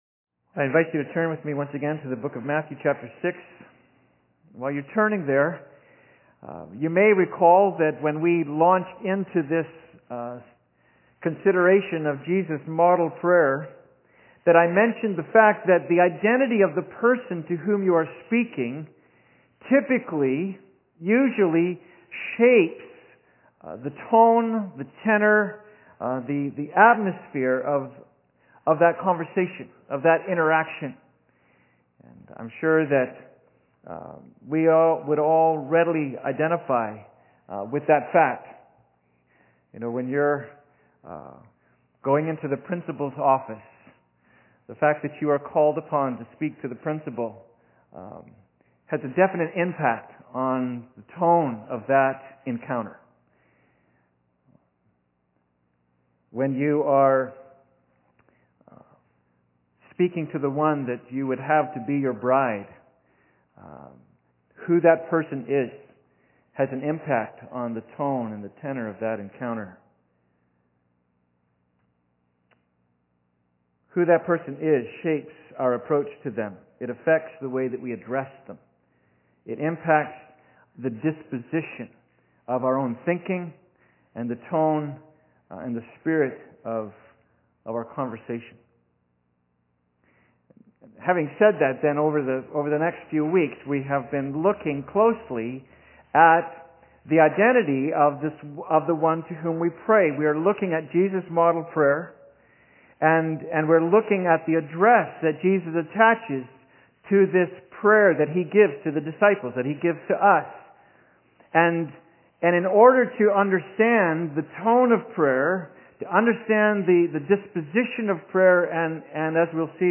Passage: Matthew 6:9 Service Type: Sunday Service